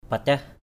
/pa-cah/